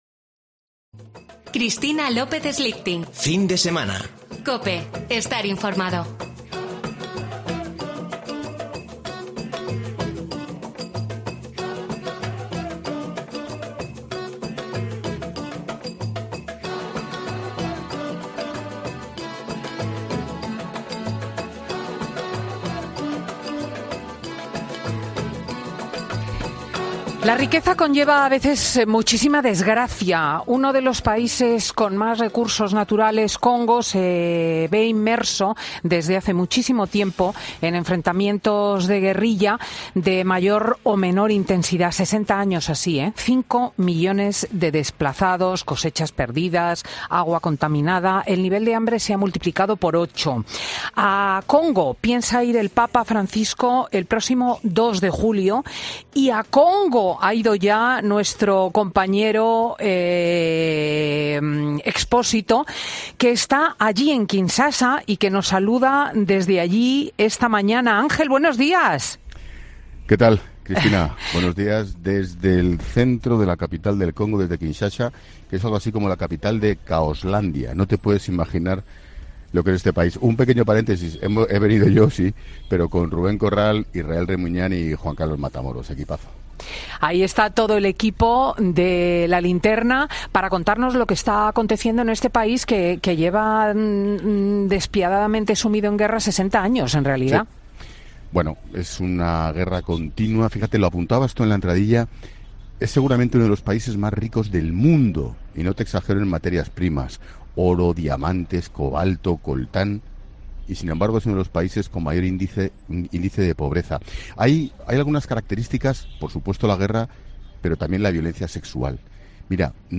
El director y presentador de 'La Linterna' cuenta en Fin de Semana con Cristina lo que está pudiendo ver en su visita al país africano, destino del Papa Francisco en unos días
"El Congo es uno de los países más ricos de África, si no te digo que del mundo, por lo menos en materias primas. Aquí continúan los mercados de oro, de diamantes, de coltán, que es con lo que se hace tu teléfono móvil, el mío o la Play de nuestros hijos y se exporta al mundo entero, fundamentalmente a través de China y de la India. Qué ocurre, que a la vez es uno de los países más pobres del planeta, con una esperanza de vida que ronda los 50 y pocos años. Por no habla de las guerras en la zona del este, es pavoroso lo que es la miseria, la pobreza y el hambre en un país inmensamente rico en materias primas", ha explicado el comunicador en su conexión con Madrid.